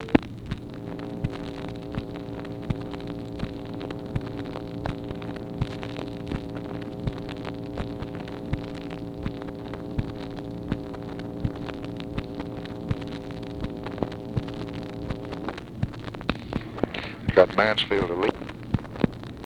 CANNOT DETERMINE TOPIC; ONLY A FEW WORDS OF LBJ'S SIDE OF A CONVERSATION ARE RECORDED
Secret White House Tapes